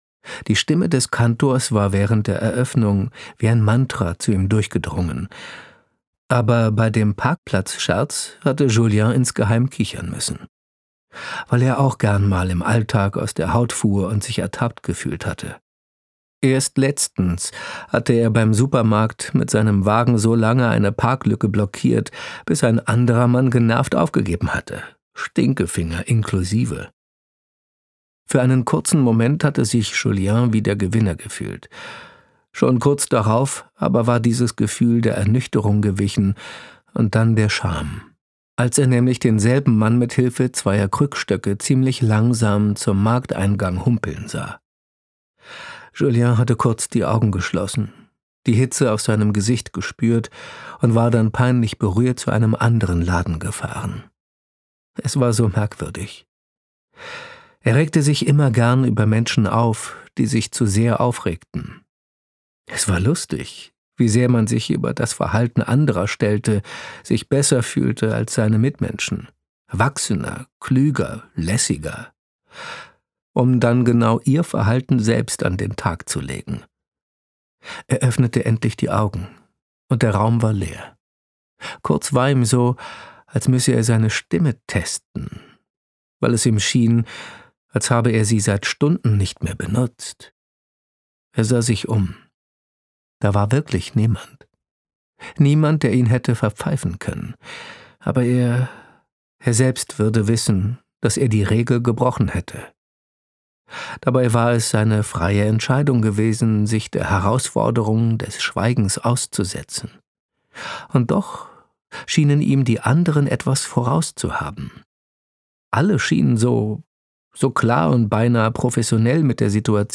Ein einzigartiges, sinnliches, beglückendes Hörbuch von Bestsellerautor Alexander Oetker: über die Kraft der Liebe, das Finden im Verlorensein – und über die Magie eines neuen Anfangs.
Gekürzt Autorisierte, d.h. von Autor:innen und / oder Verlagen freigegebene, bearbeitete Fassung.